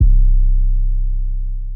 808 (Quay QC Master).wav